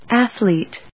/ˈæθliːt(米国英語), ˈæθ.liːt(英国英語)/
フリガナアッスリート